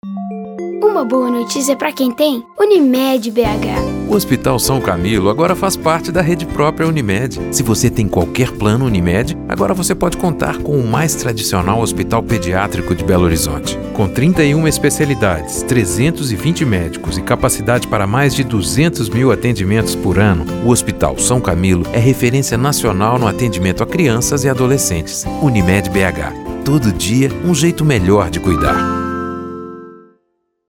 • Spot para rádio